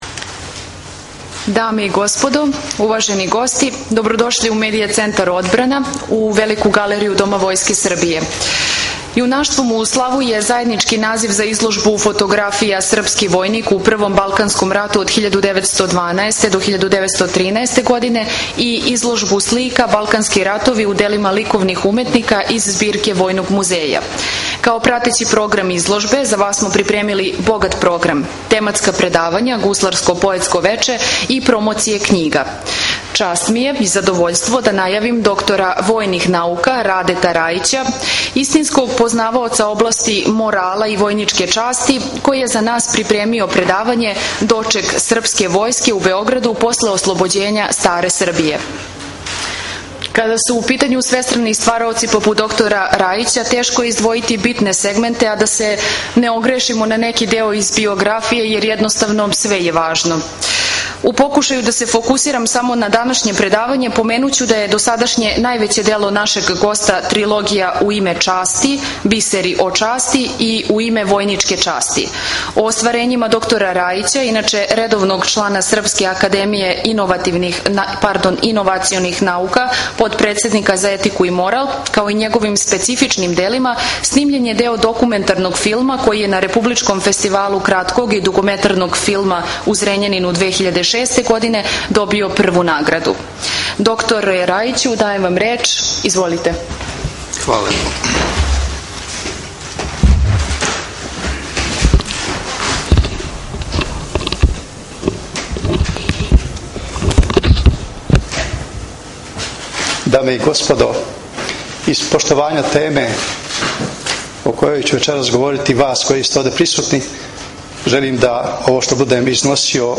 ДОКУМЕНТА Предавање (57 минута; 26 МБ) mp3